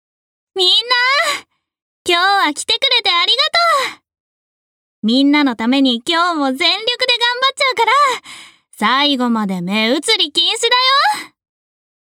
↓ここからはボイスサンプルです。
少年・中高生